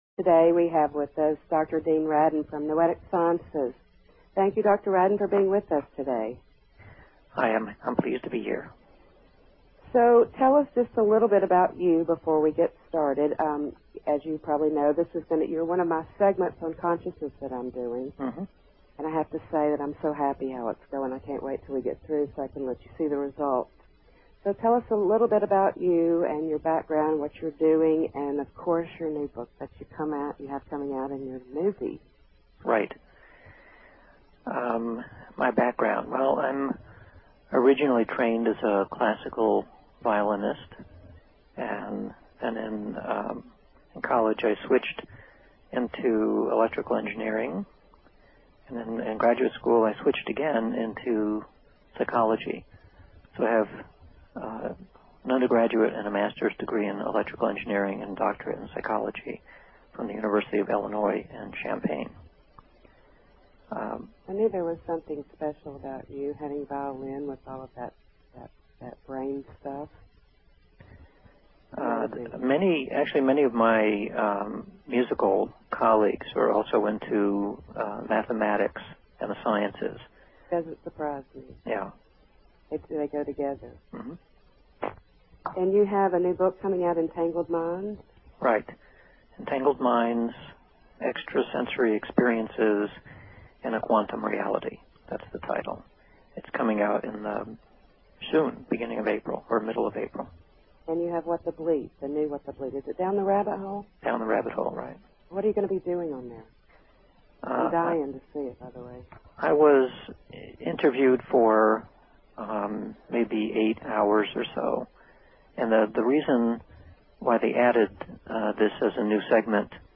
Talk Show Episode, Audio Podcast, The_Infinite_Consciousness and Courtesy of BBS Radio on , show guests , about , categorized as